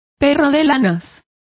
Complete with pronunciation of the translation of poodle.